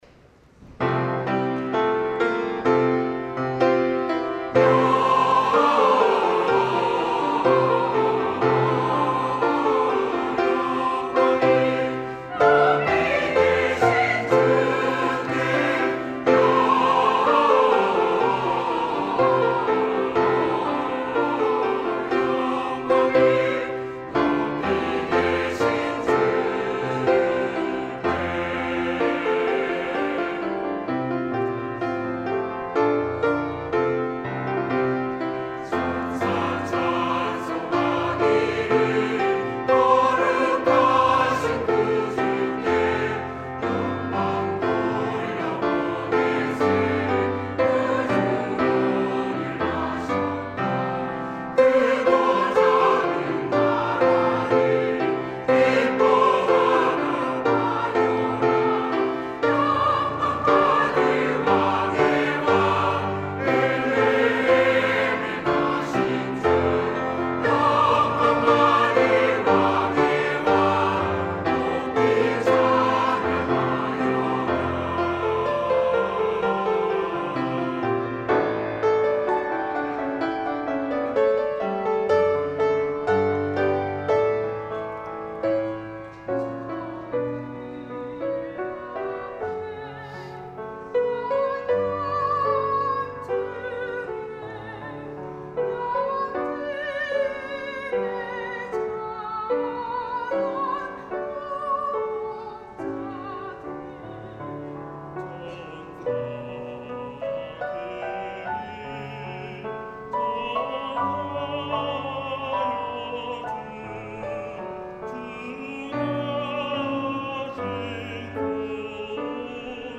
찬양 :: 141214 천사들의 노래
"천사들의 노래"-시온찬양대